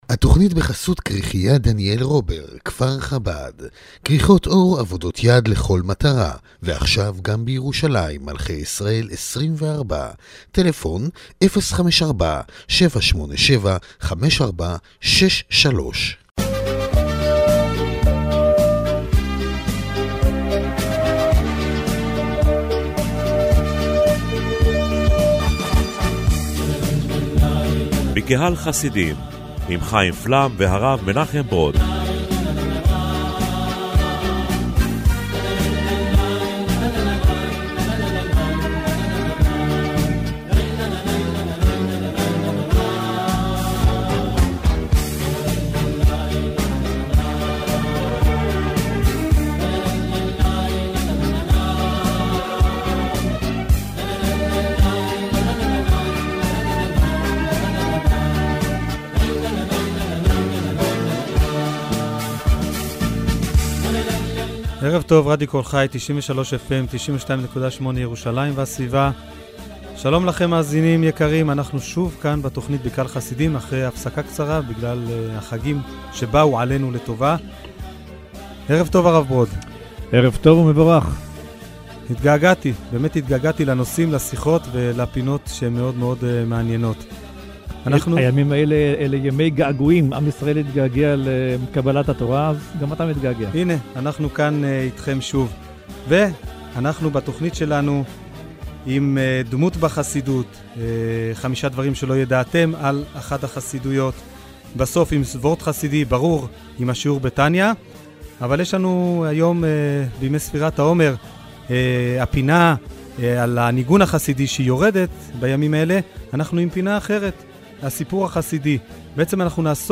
לרגל ימי ספירת העומר עברה עברה השבוע תכנית הרדיו השבועית 'בקהל חסידים' למתכונת חדשה.